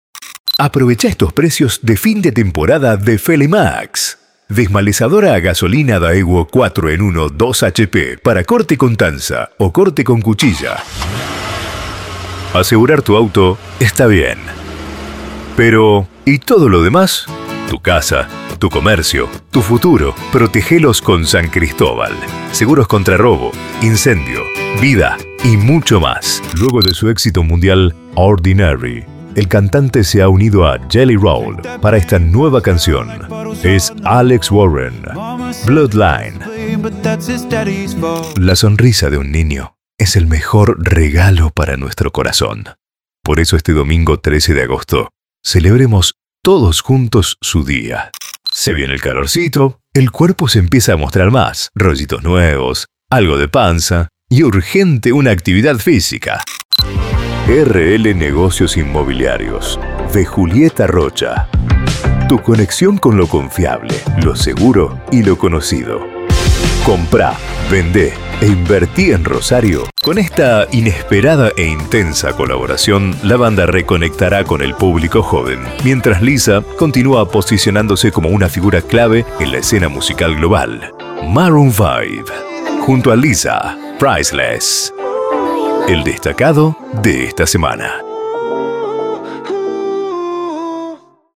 Banco de Voces Masculinas - Estudio Gabarra | Especialistas en Producción de Audio para radio y Contenido Creativo.
Contrata un Locutor Profesional.